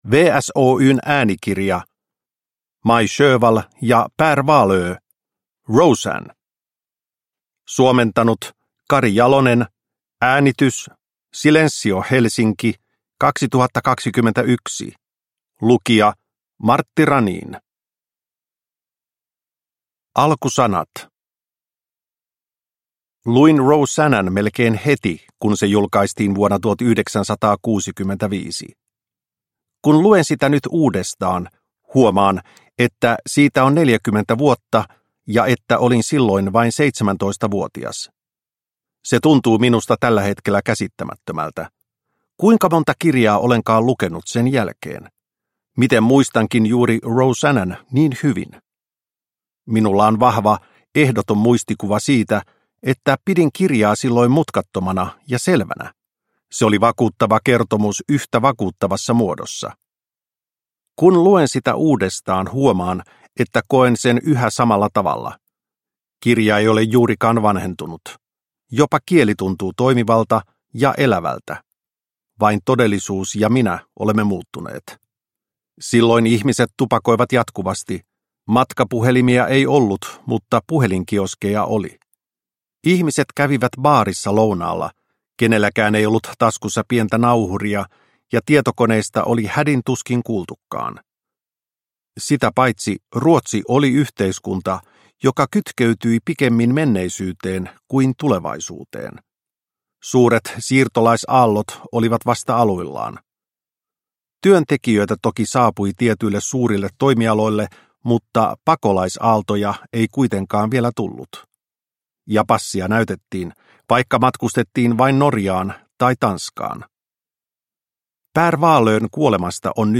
Roseanna – Ljudbok – Laddas ner